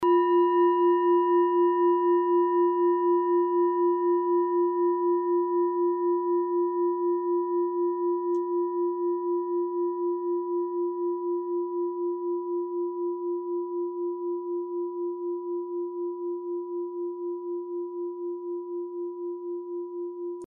Kleine Klangschale Nr.28 Bengalen
Planetentonschale: Jahreston (Plato)
Sie ist neu und wurde gezielt nach altem 7-Metalle-Rezept in Handarbeit gezogen und gehämmert.
Hörprobe der Klangschale
(Ermittelt mit dem Minifilzklöppel)
Klangschalen-Gewicht: 460g
Klangschalen-Öffnung: 13,8cm
kleine-klangschale-28.mp3